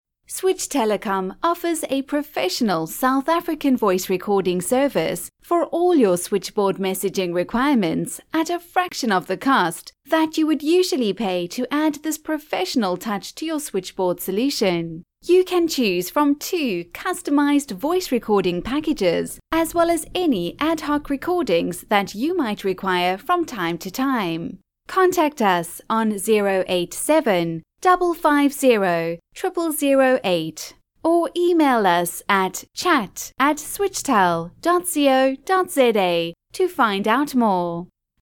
Professional Voice recording (IVR) for your Hosted Switchboard
• Connect with your customers because you are using a professional South African voice artist.
Female:
Professional-Voice-Recording.wav